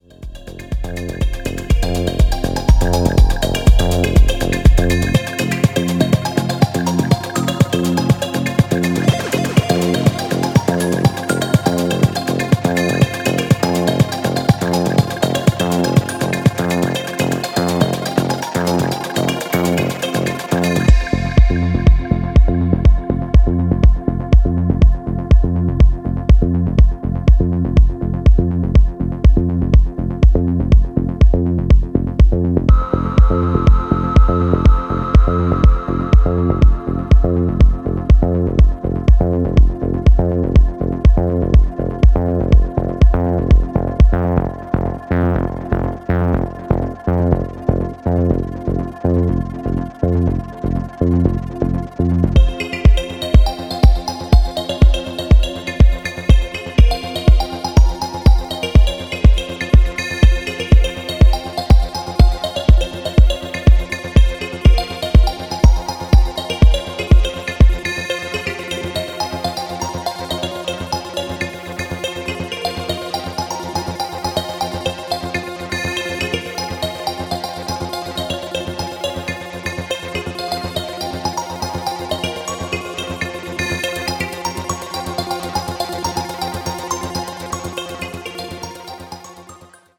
余りの眩さに覚醒必至なBPM122の低重心トラック
トランシーを通り越して遠大サイケデリックな音作り、シネマティックなアレンジ